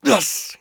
pain_5.ogg